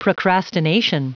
Prononciation du mot procrastination en anglais (fichier audio)
Prononciation du mot : procrastination